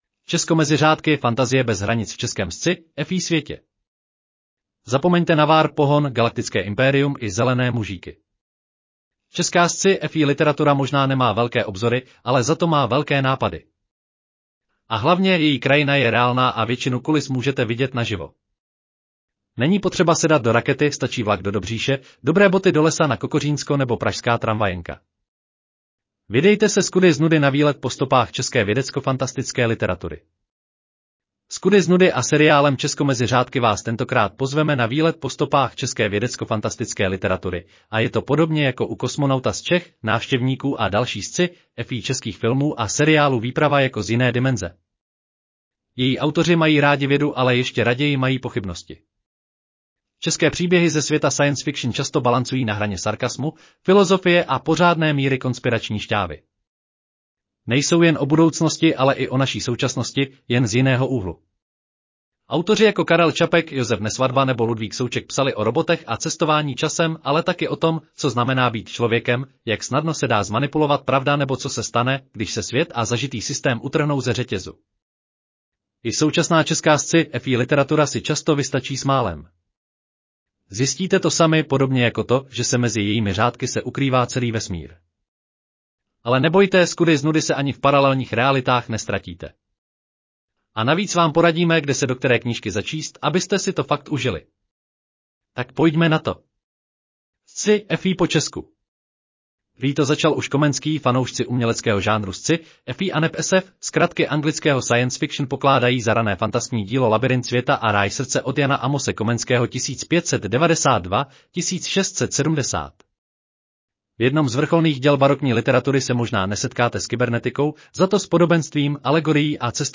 Audio verze článku Česko mezi řádky: Fantazie bez hranic v českém sci-fi světě